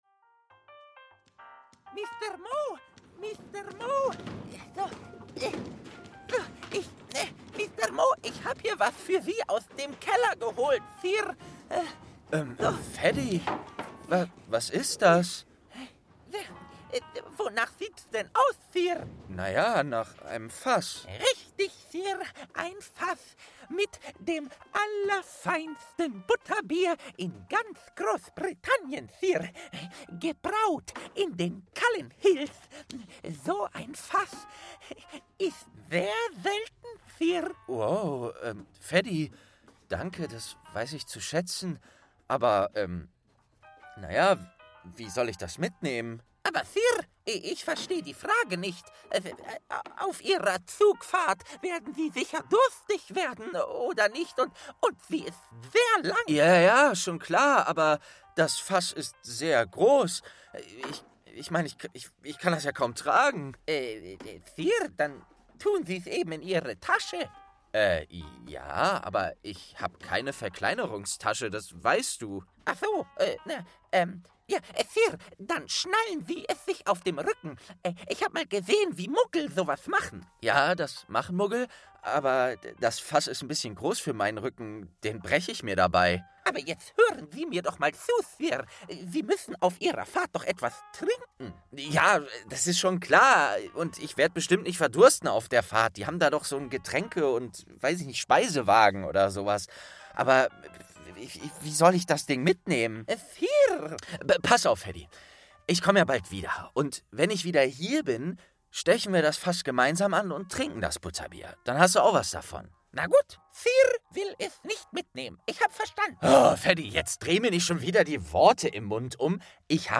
Bahnhof Hogsmeade: ein Abenteuer beginnt - Geschichten aus dem Eberkopf ~ Geschichten aus dem Eberkopf - Ein Harry Potter Hörspiel-Podcast Podcast